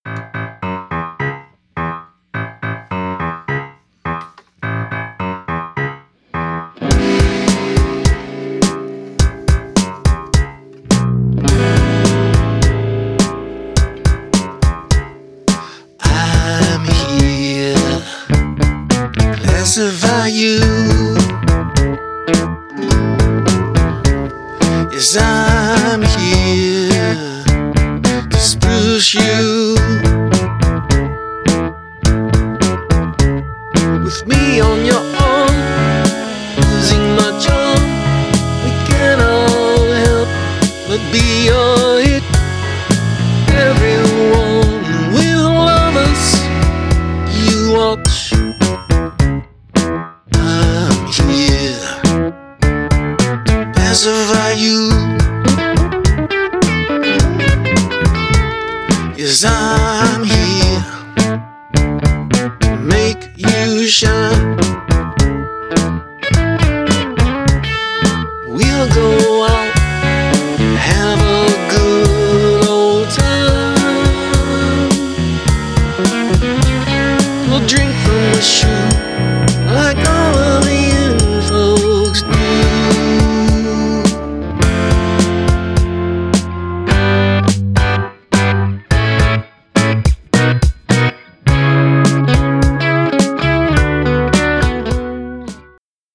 Not the title I would prefer but beggars have to accept etc. Another work in progress.
Guitar is intended to be dark. Cymbals have a bit too much noise I think - what say you?